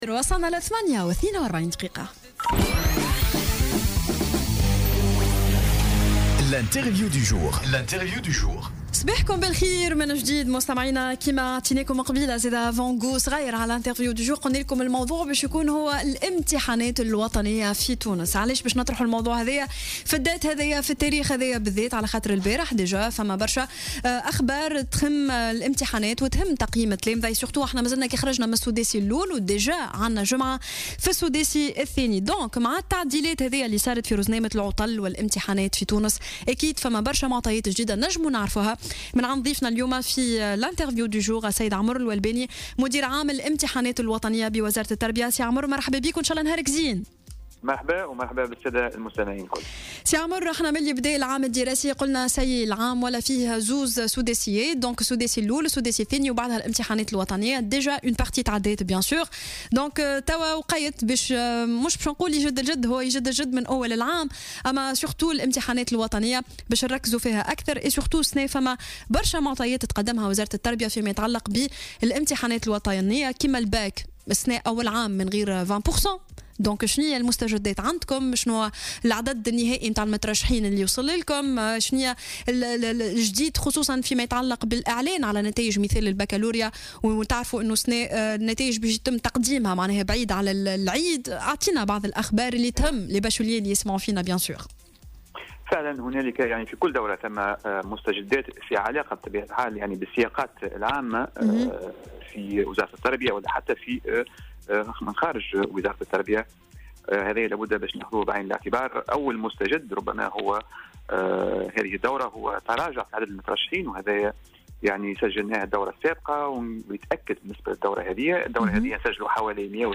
Omar Ouelbani, directeur général des examens nationaux au ministère de l'Education est intervenu ce vendredi 27 janvier 2017 sur Jawhara Fm pour présenter quelques nouveautés dans le calcul de la moyenne du Baccalauréat.